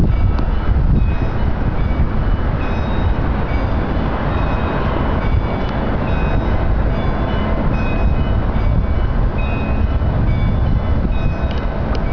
Click here to listen to the church bells ringing.